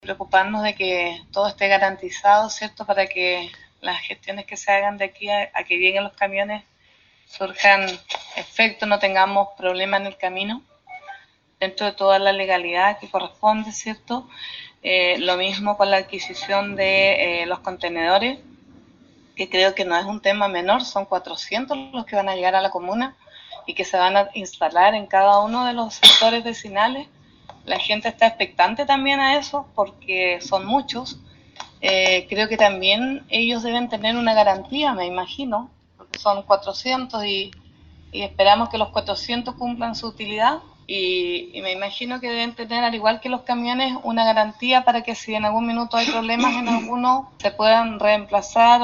Conforme se manifestó la concejala María Luisa Rojas, tras la aprobación  en el concejo municipal